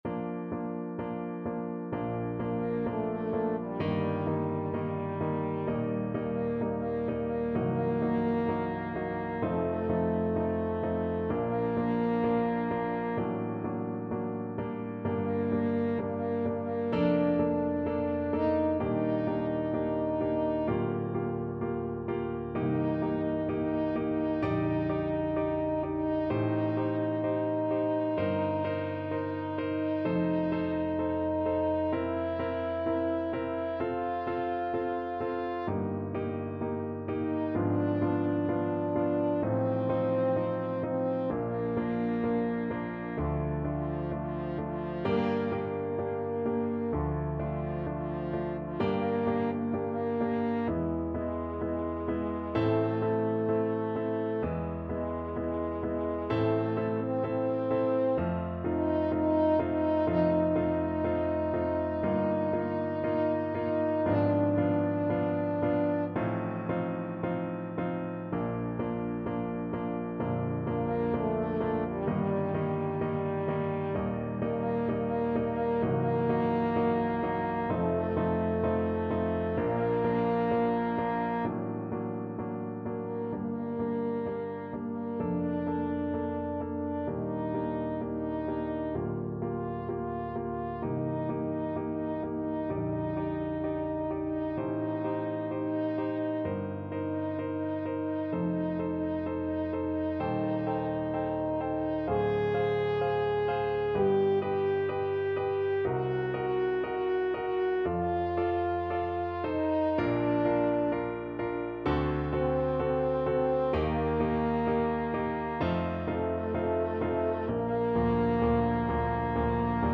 Nicht zu schnell = 64 Nicht zu schnell